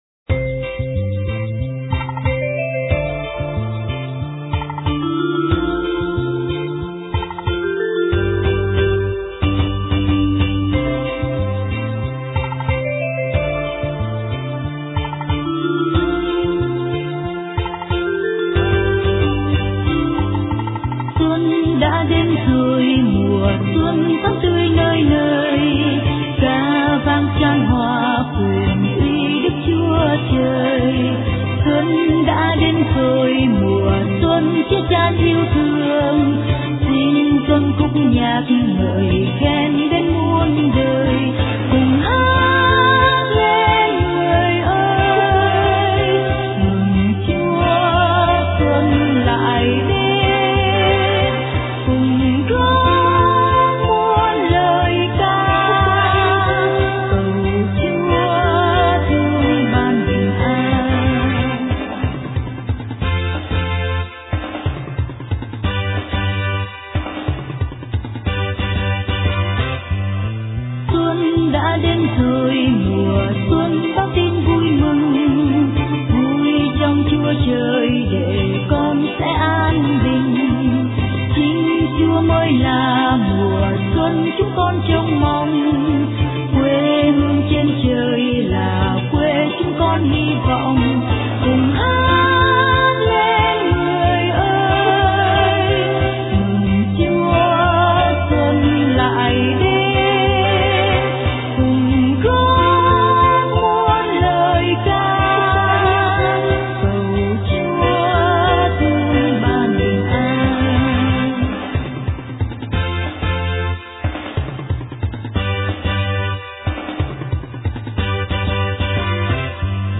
* Thể loại: Mừng Xuân